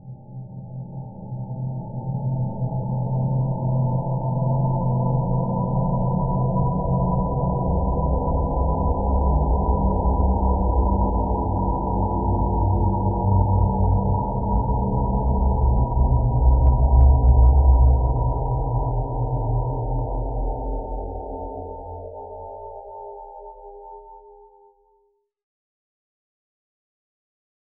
Spectral Drone 01.wav